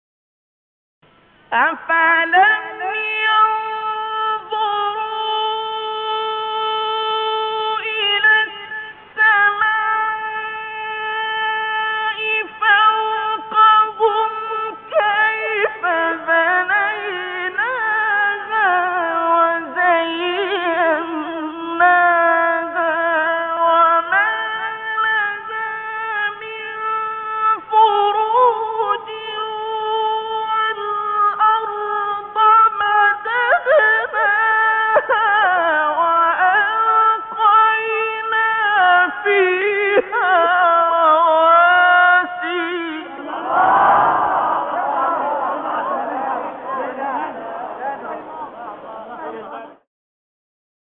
فایل‌های تلاوت تقلیدی
قطعه تلاوت تقلیدی استاد عبدالباسط